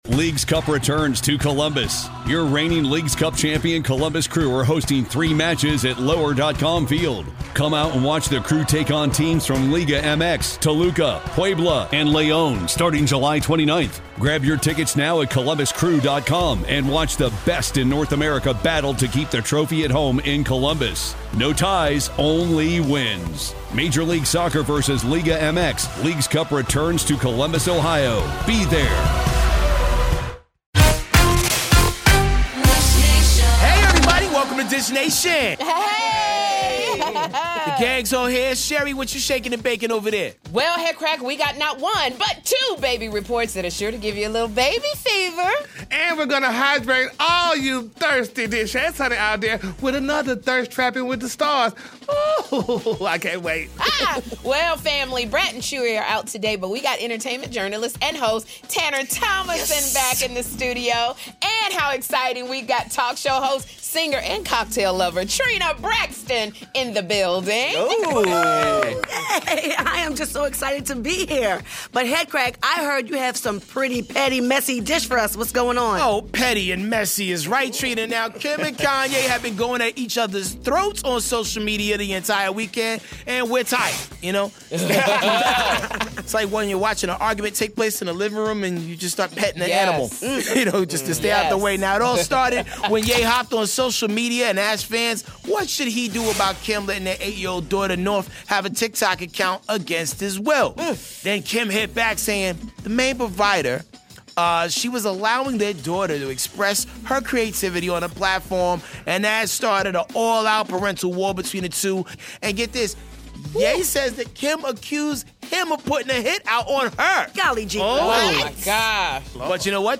are in studio dishin' with us